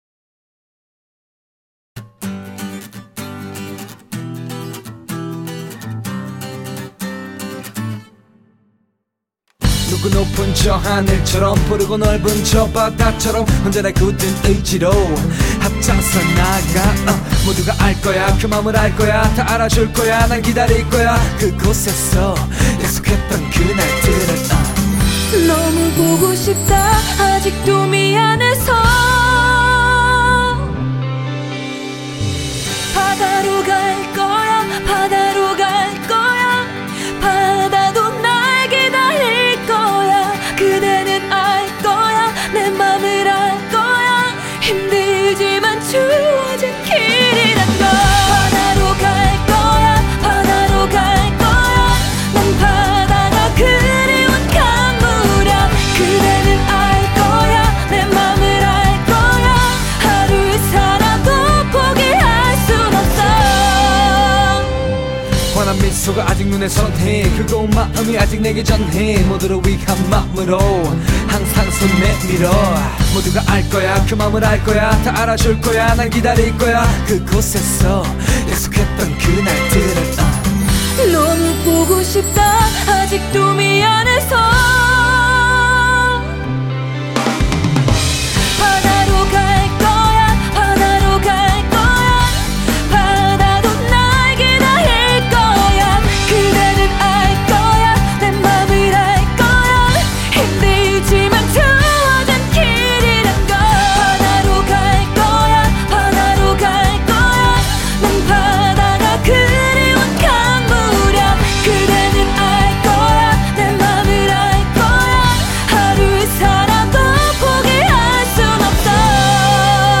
혼성 듀엣